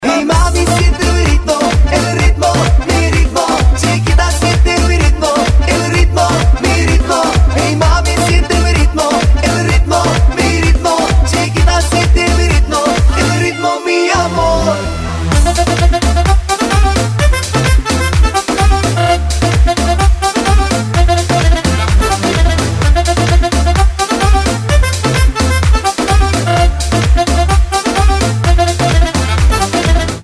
• Качество: 128, Stereo
латинские
латиноамериканские